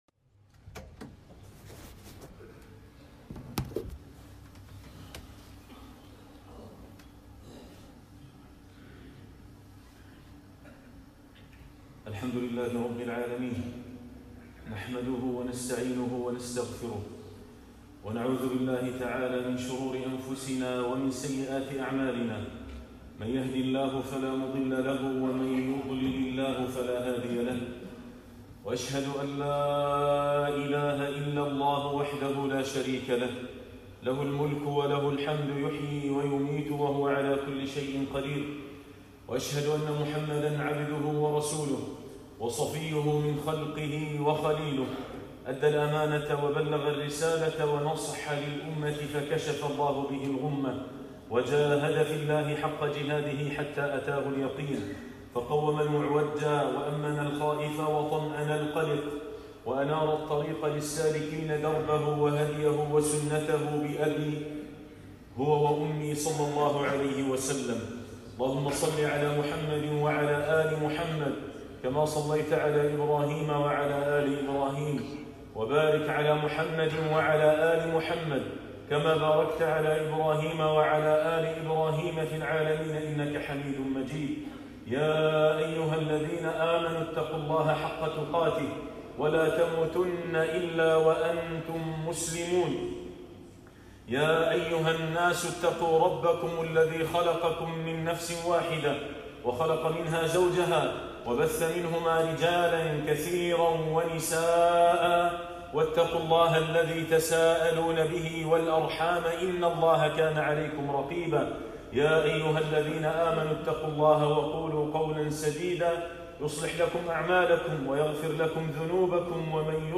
منزلة الشكر - خطبة